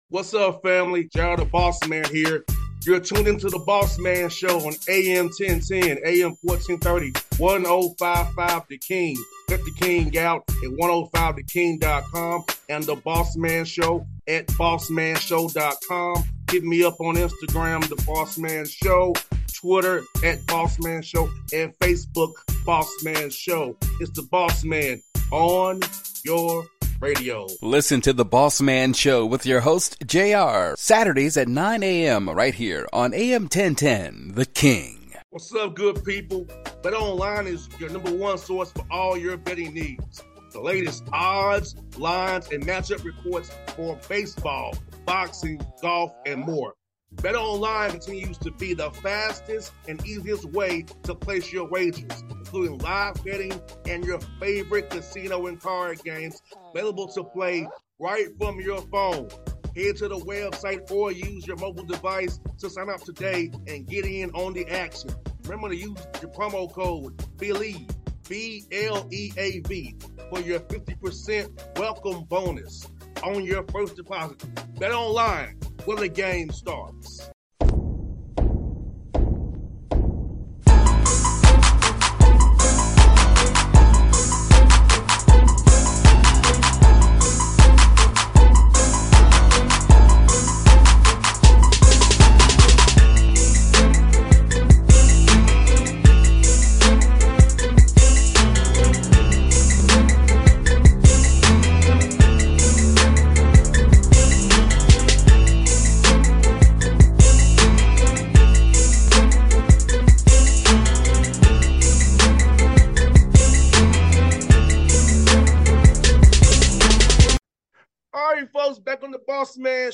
Trent Dilfer Interview